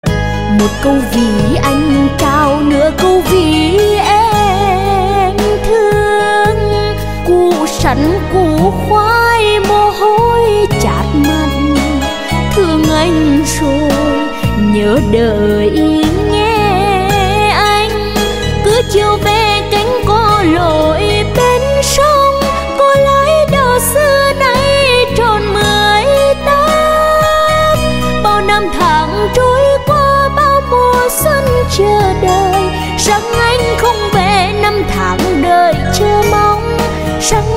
Nhạc Chuông Trữ Tình